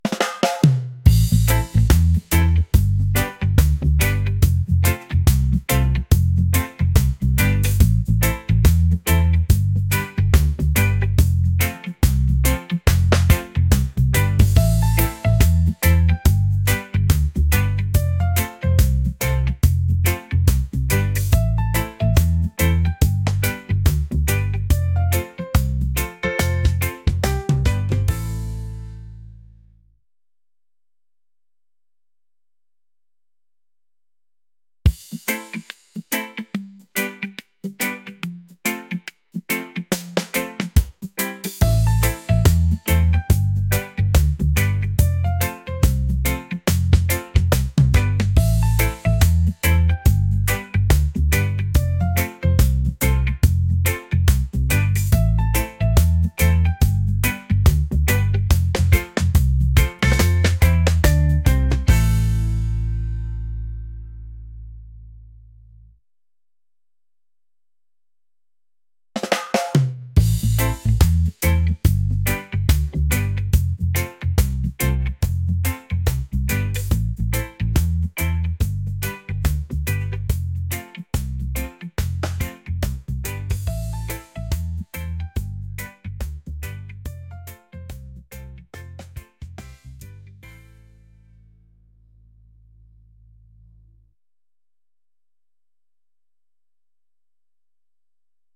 reggae | laid-back | upbeat